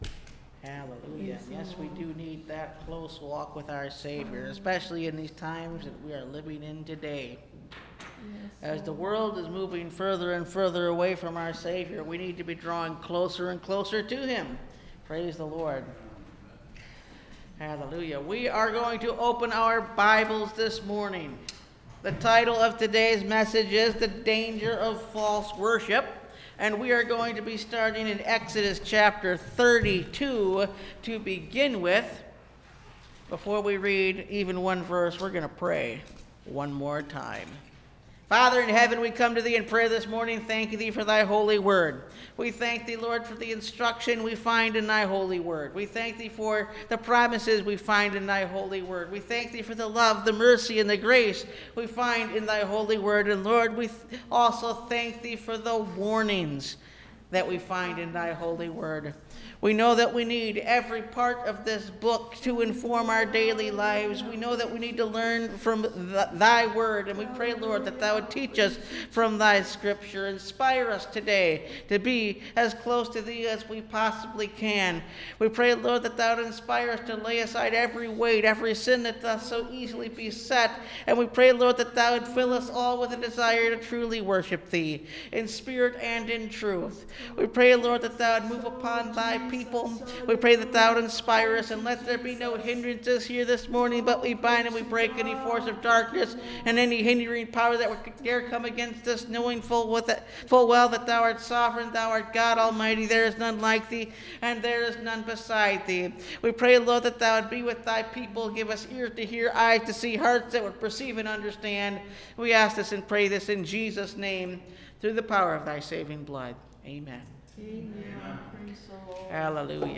The Danger of False Worship (Message Audio) – Last Trumpet Ministries – Truth Tabernacle – Sermon Library